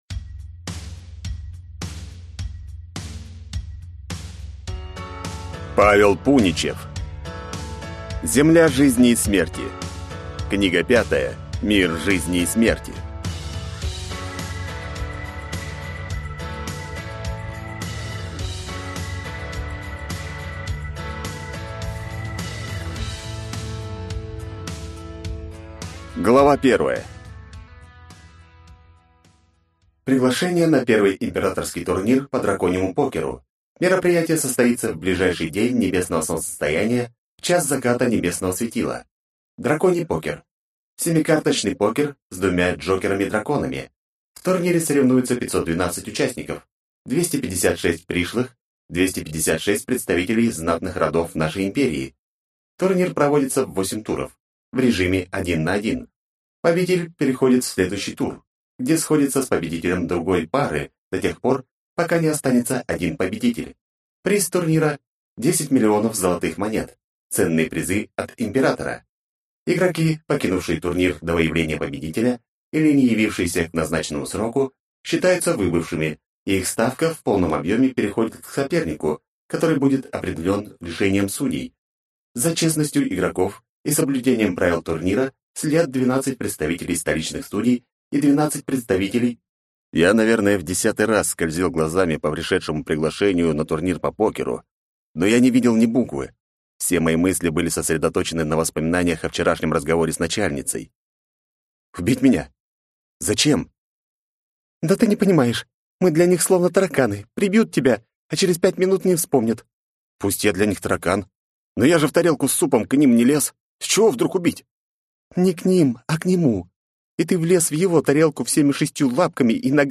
Аудиокнига Земля жизни и смерти. Книга 5. Мир жизни и смерти | Библиотека аудиокниг